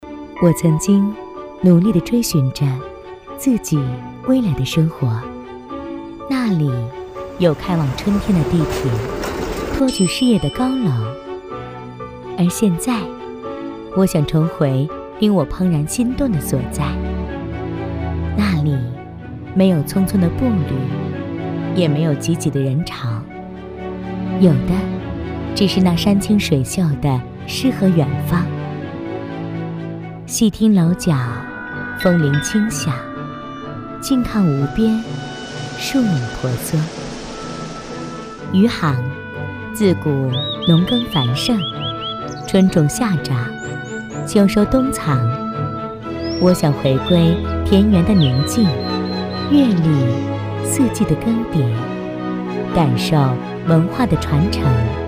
旅游宣传片配音